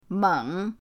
meng3.mp3